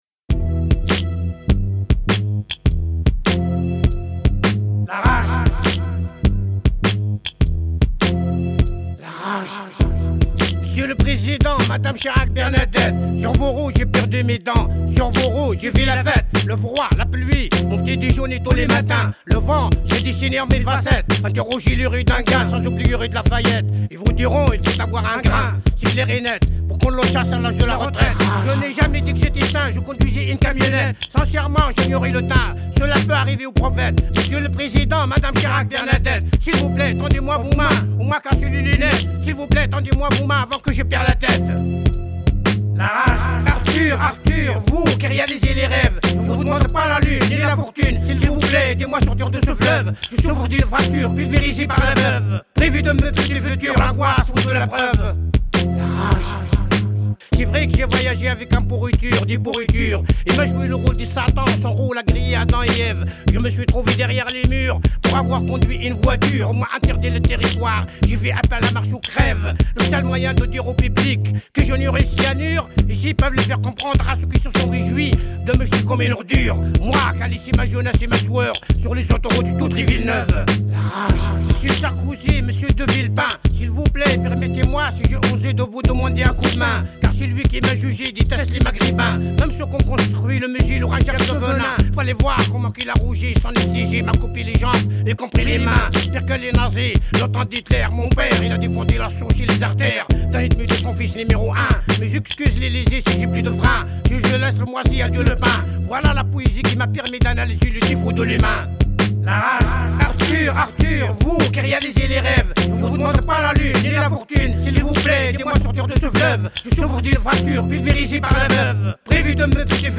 Émission du 13 février 2026 animée par